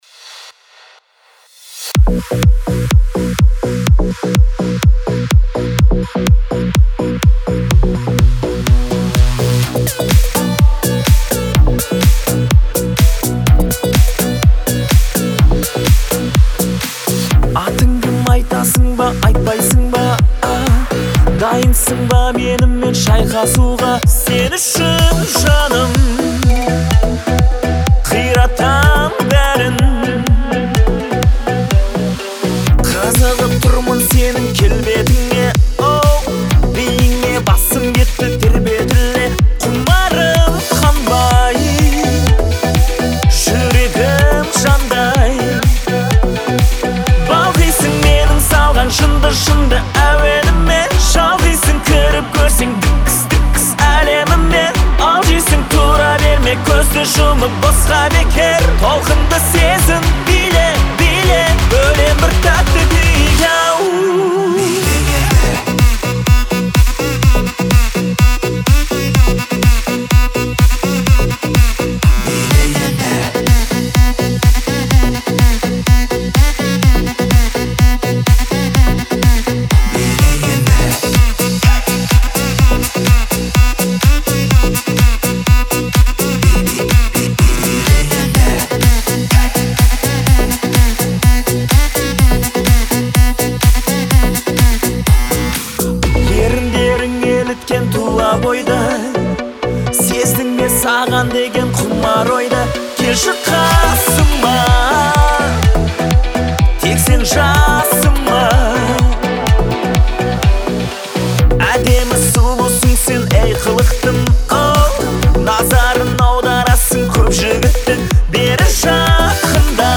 это яркий пример казахской поп-музыки